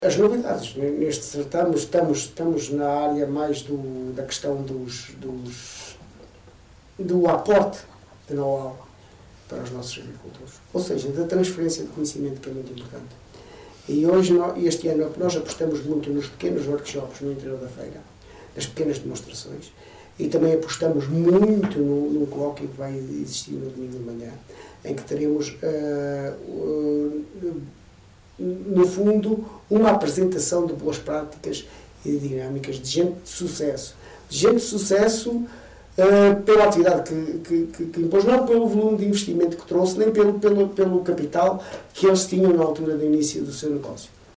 A mostra que passou a ser feira tem este ano como novidade maior o investimento na formação dos agricultores, com workshops, demonstrações e um colóquio, avançou em entrevista à Onda Livre Carlos Barroso, vice-presidente da autarquia.